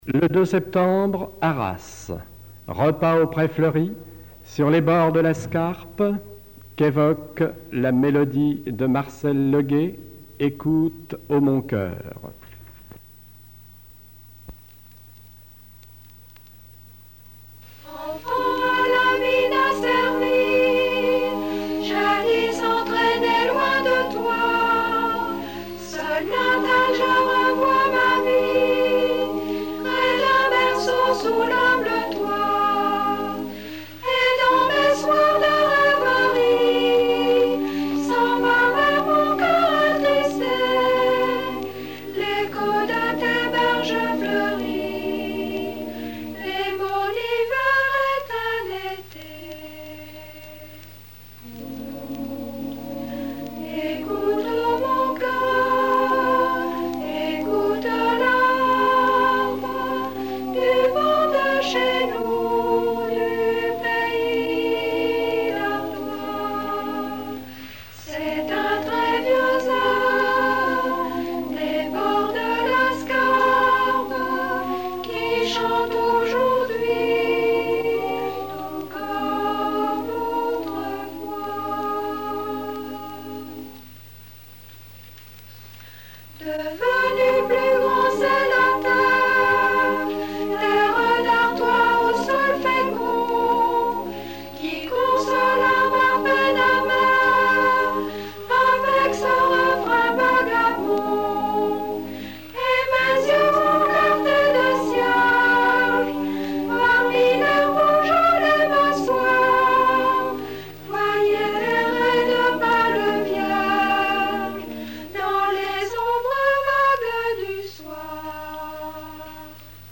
journée amicale des directeurs de CRDT
Genre strophique
Catégorie Pièce musicale inédite